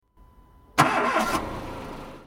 جلوه های صوتی
دانلود صدای ماشین 11 از ساعد نیوز با لینک مستقیم و کیفیت بالا
برچسب: دانلود آهنگ های افکت صوتی حمل و نقل دانلود آلبوم صدای انواع ماشین از افکت صوتی حمل و نقل